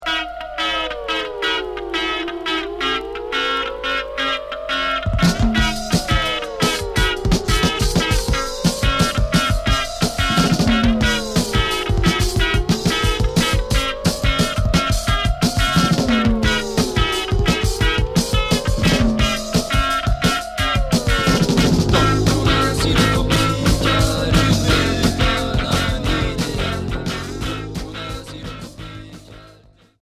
Cold wave Unique 45t retour à l'accueil